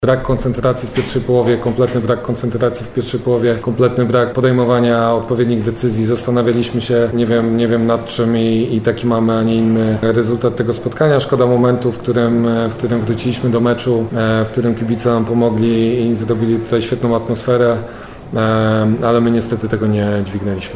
w pomeczowej konferencji